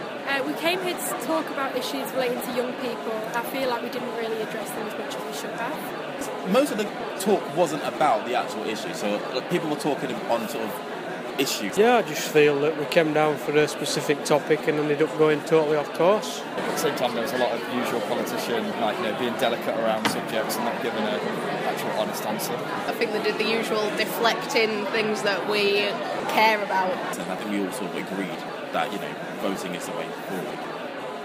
Young people react to the panellists at the end of the idebate in Leeds.